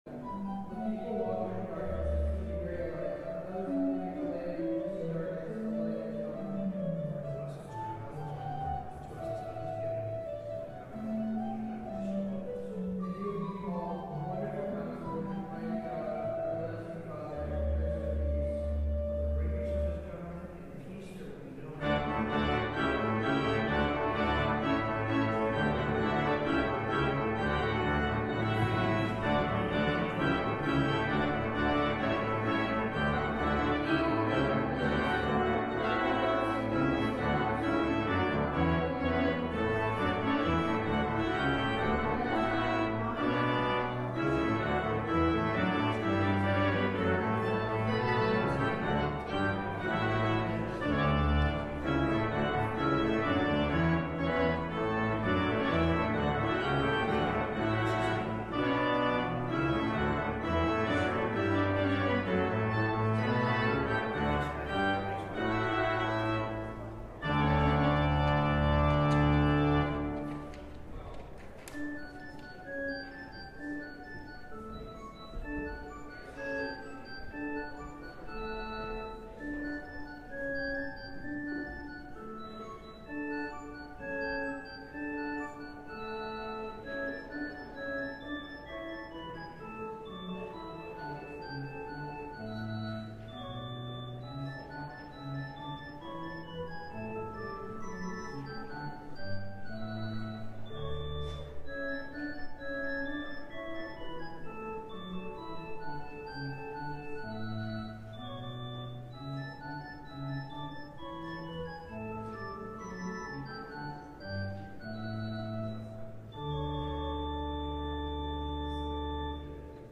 LIVE Morning Worship Service - Preparing the Way of the Lord: Death